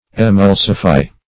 Emulsify \E*mul"si*fy\, v. t. [Emulsion + -fy.]